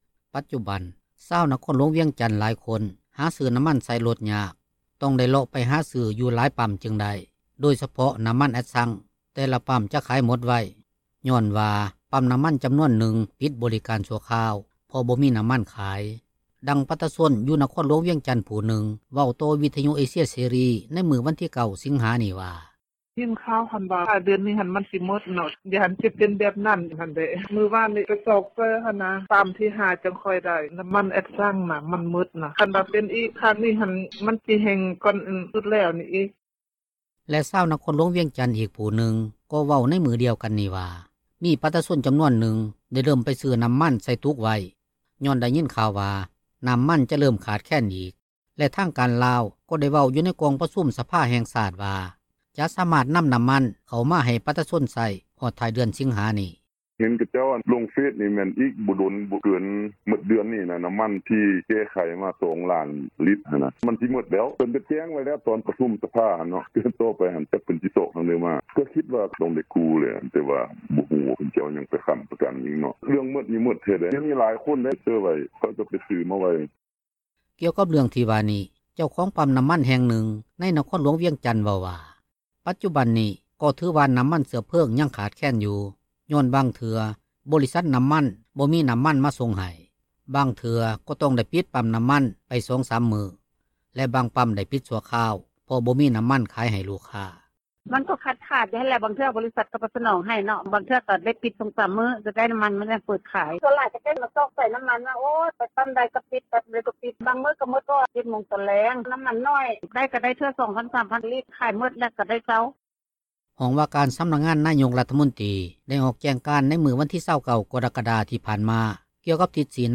ດັ່ງປະ ຊາຊົນຢູ່ນະຄອນຫລວງວຽງຈັນ ຜູ້ນຶ່ງເວົ້າຕໍ່ວິທຍຸເອເຊັຽເສຣີໃນມື້ວັນທີ 9 ສິງຫານີ້ວ່າ:
ດັ່ງເຈົ້າໜ້າທີ່ ຜແນກອຸດສາຫະກັມ ແລະການຄ້າ ນະຄອນຫລວງວຽງຈັນ ທ່ານນຶ່ງເວົ້າໃນມື້ດຽວກັນນີ້ວ່າ: